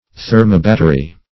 Search Result for " thermobattery" : The Collaborative International Dictionary of English v.0.48: Thermobattery \Ther`mo*bat"ter*y\, n. [Thermo- + battery.] A thermoelectric battery; a thermopile.